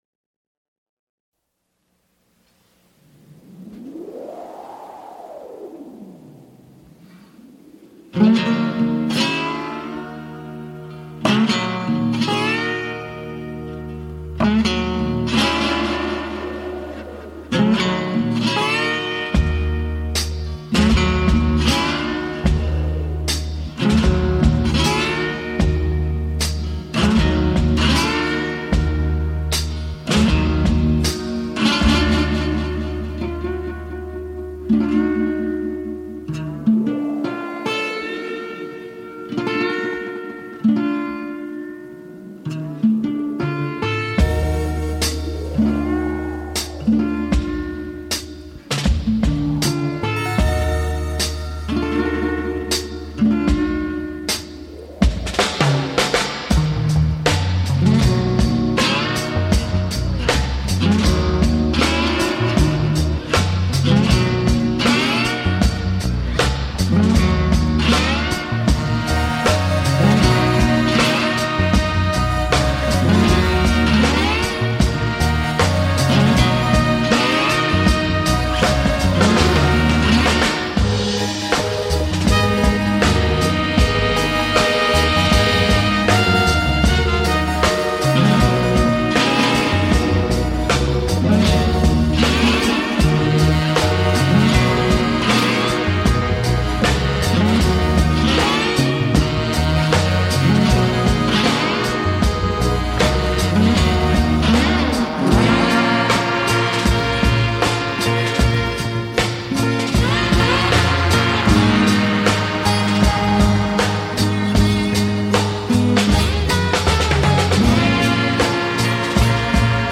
instrumental soul sound
a slightly darker tone
” with it’s Ennio Morricone vibe to it in the slide guitar.